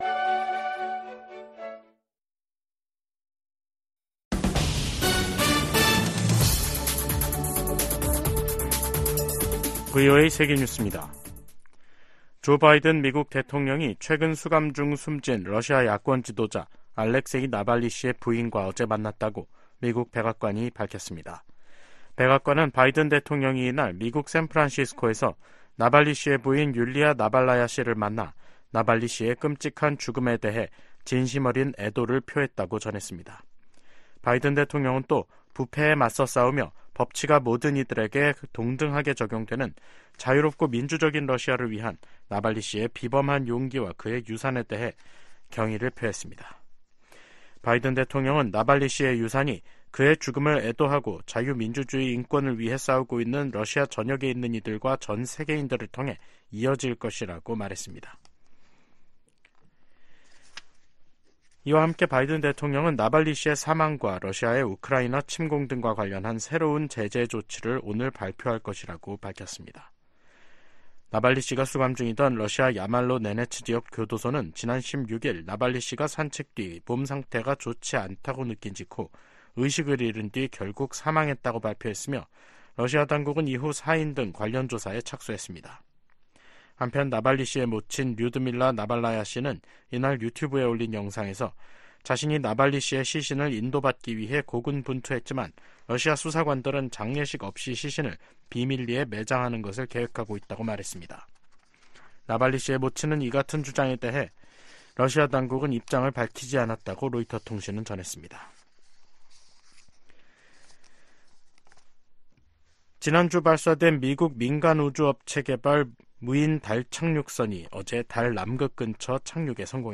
VOA 한국어 간판 뉴스 프로그램 '뉴스 투데이', 2024년 2월 23일 2부 방송입니다. 미국·한국·일본 외교 수장들이 리우데자네이루 주요20개국(G20) 외교장관회의 현장에서 역내 도전 대응 방안을 논의했습니다. 보니 젠킨스 미 국무부 군비통제·국제안보 차관이 북한-러시아의 군사 협력을 심각한 우려이자 심각한 위협으로 규정했습니다. 빅토리아 눌런드 국무부 정무차관은 러시아가 포탄을 얻는 대가로 북한에 어떤 기술을 넘겼을지 누가 알겠느냐며 우려했습니다.